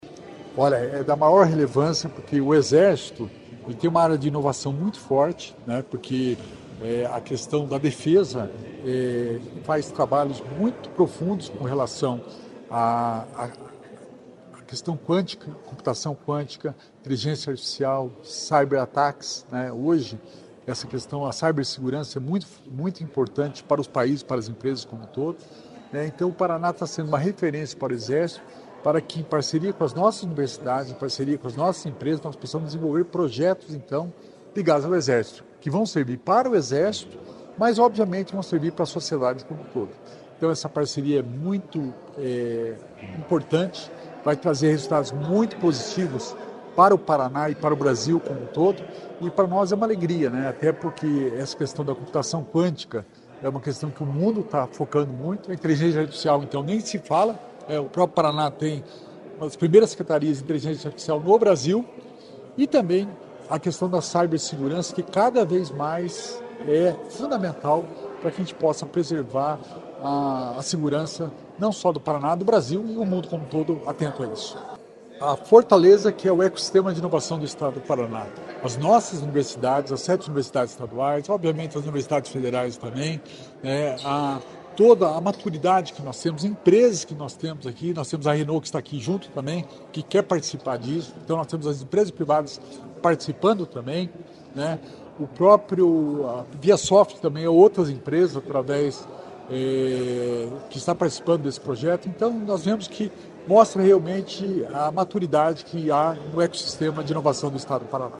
Sonora do secretário Estadual da Inovação e Inteligência Artificial, Alex Canziani, sobre a parceria com Exército para pesquisas com IA e cibersegurança